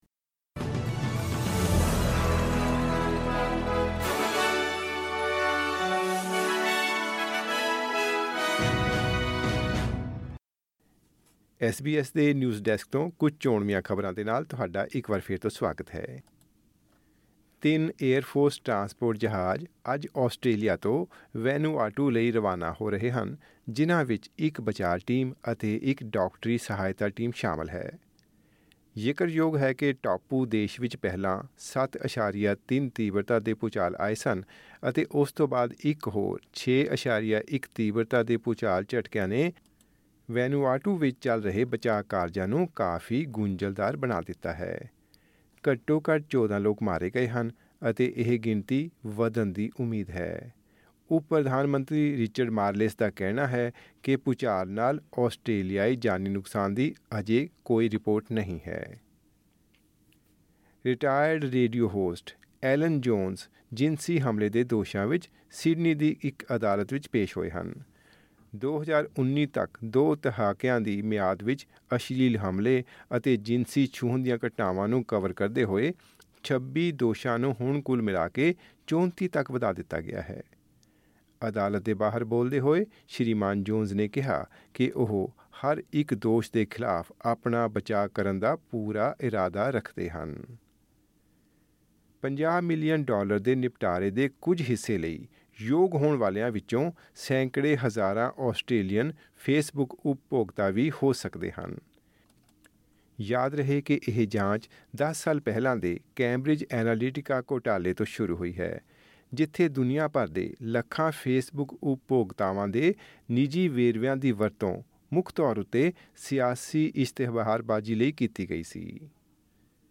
ਖਬਰਨਾਮਾ: ਵੈਨੂਆਟੂ ਵਿੱਚ ਆਏ ਭੁਚਾਲਾਂ ਨੇ ਮਚਾਈ ਤਬਾਹੀ, ਆਸਟ੍ਰੇਲੀਆ ਨੇ ਭੇਜੀ ਮਦਦ